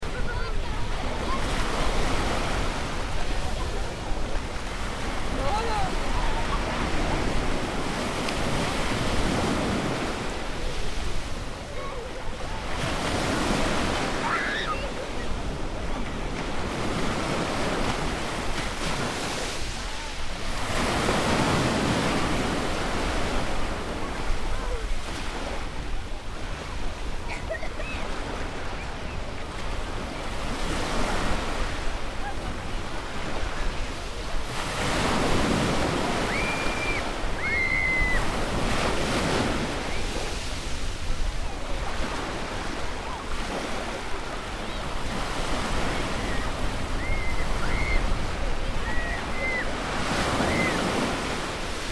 دانلود آهنگ موج 10 از افکت صوتی طبیعت و محیط
دانلود صدای موج 10 از ساعد نیوز با لینک مستقیم و کیفیت بالا
جلوه های صوتی